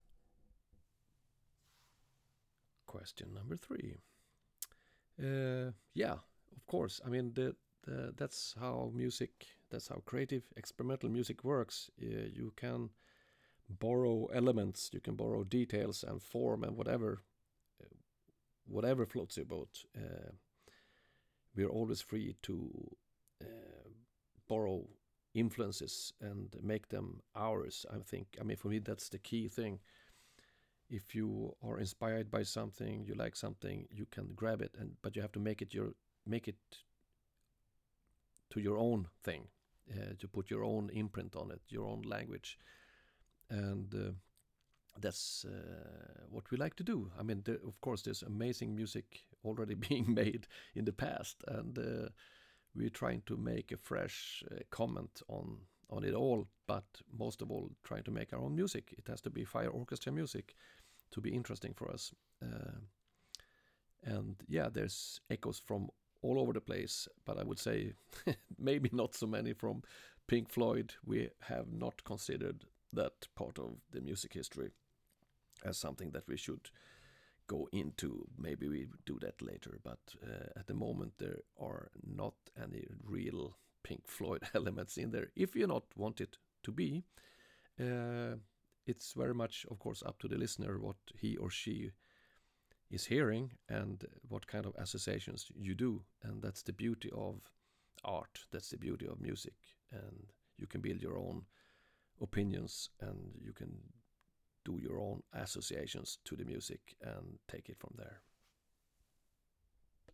More snippets from my Mats Gustafsson interview